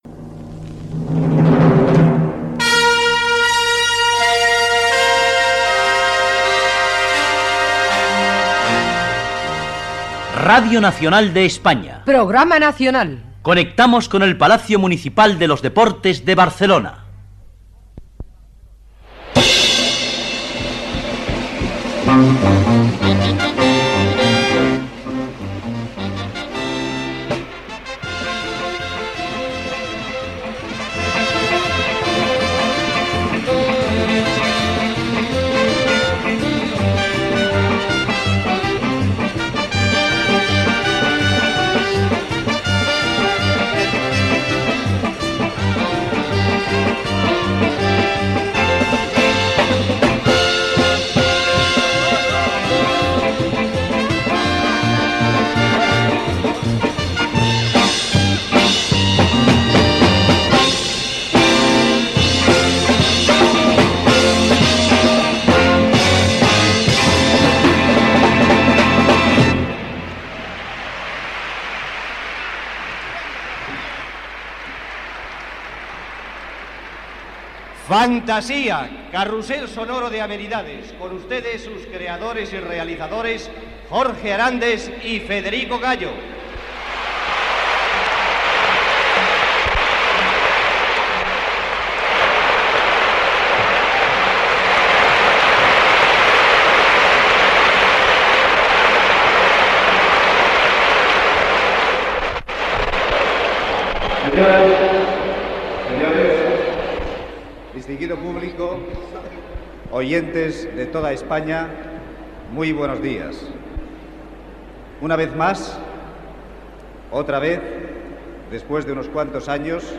Emissió des del Palau d'Esports de Barcelona amb motiu del 20è aniversari de RNE a Barcelona.